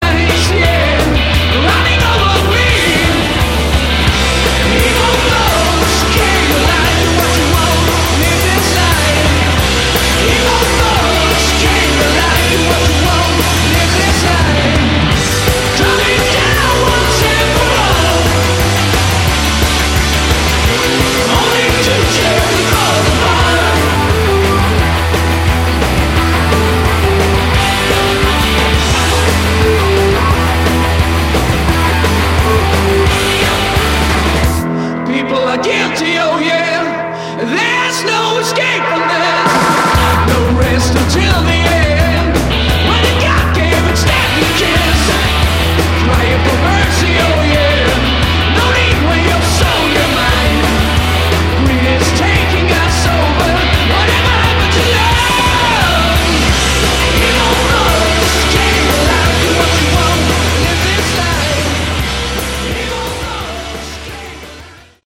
Category: Hard ROck
vocals, guitar
drums
bass